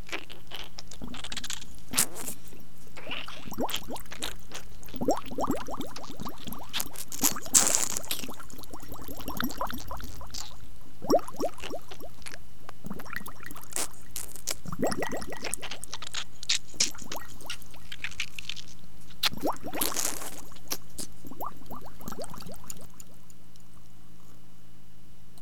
strange_bubbles.ogg